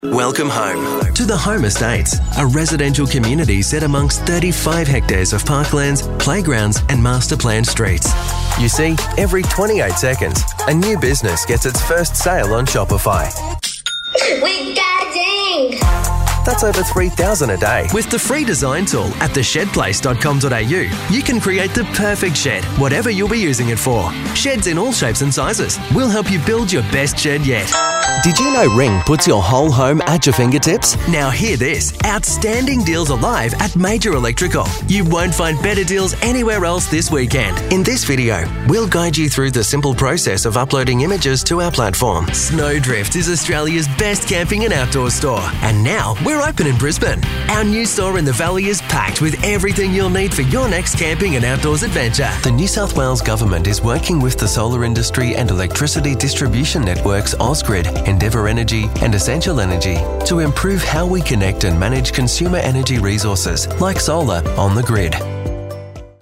English (Australian)
Modulated, clear Australian accent.
Professional
Clear
Corporate